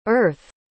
Planeta Tradução em Inglês Pronúncia